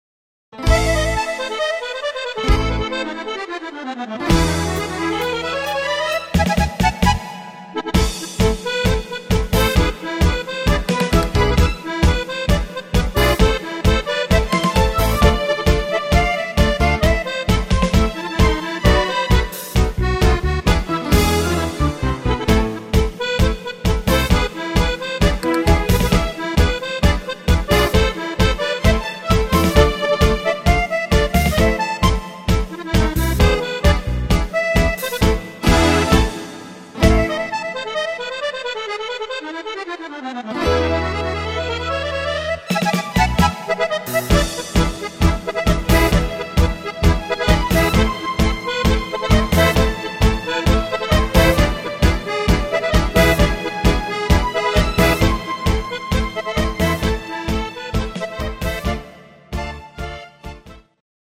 instrumental Akkordeon